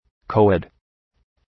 Προφορά
{‘kəʋ,ed}